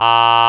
aa-pout-115Hz-8kHz.wav